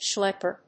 • : -ɛpə(ɹ)